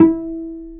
Pizz(2)_E4_22k.wav